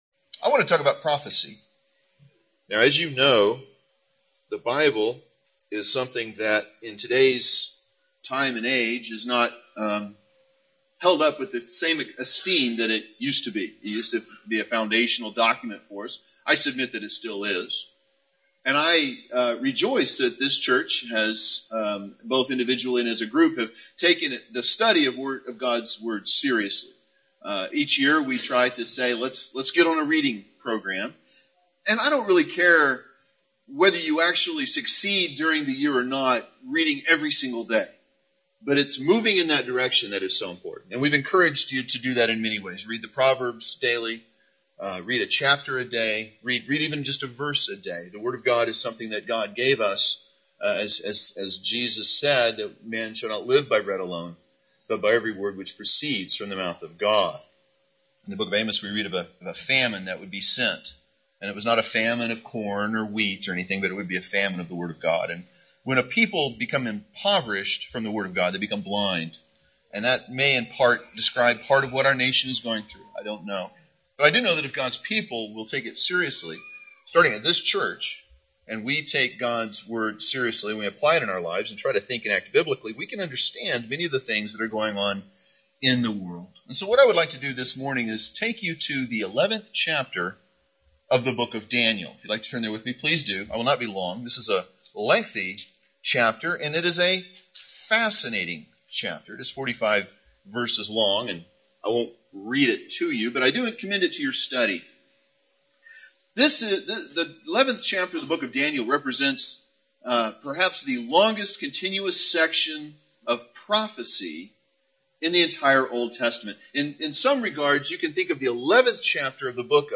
Sermon • Bethlehem Primitive Baptist Church of Oklahoma City • Page 109
Recorded in Cincinnati, OH (1980)